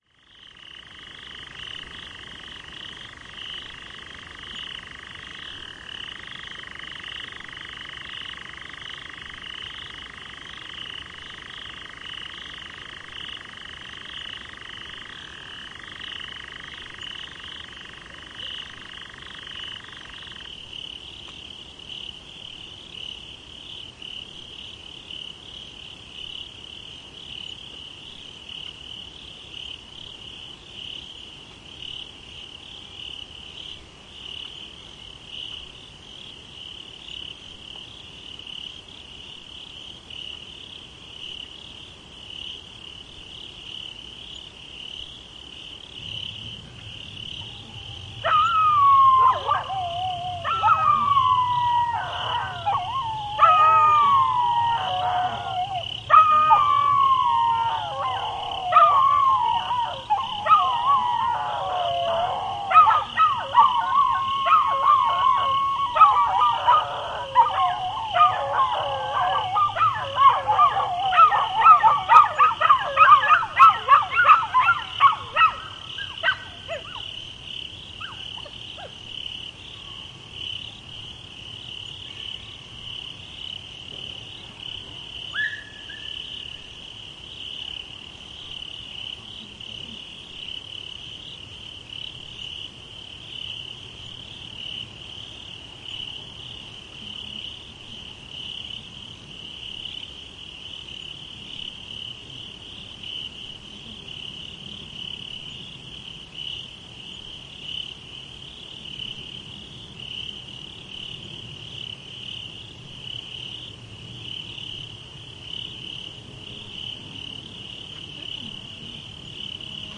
自然界 " 夜晚的蟋蟀和狗
描述：NIGHT蟋蟀和狗用变焦记录
Tag: 乱叫 晚上 动物 蟋蟀 性质 现场记录